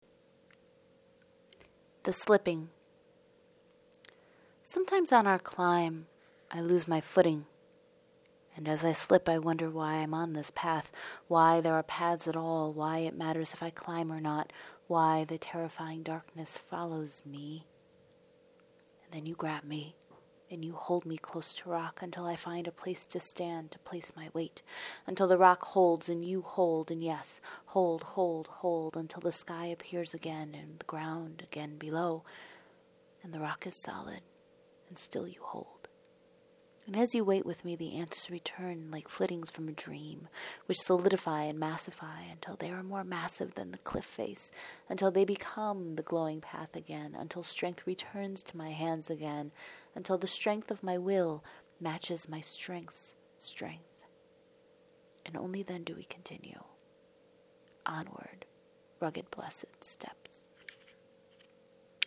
[the audio link is below, for those of you who would like a listening prayer-meditation]